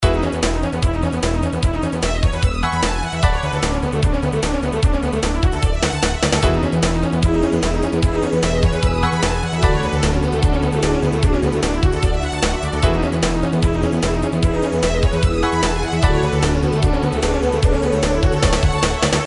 SFX快节奏赶时间影视音效下载
SFX音效